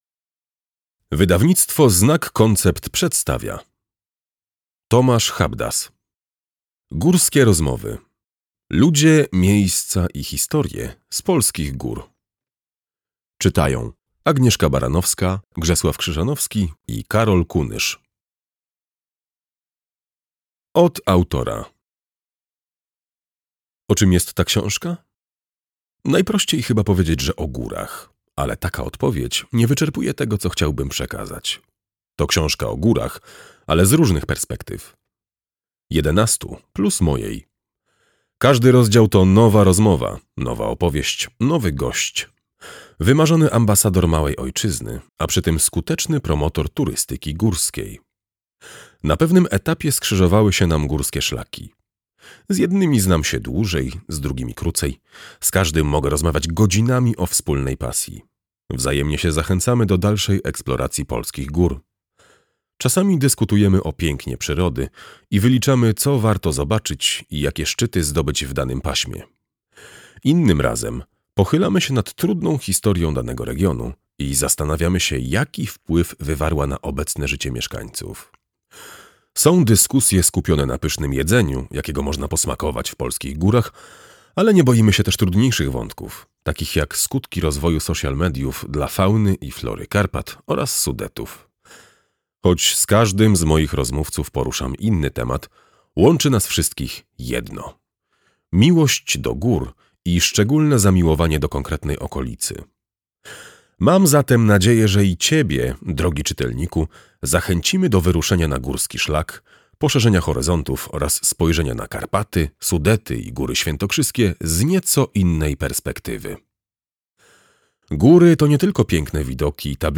audiobook + książka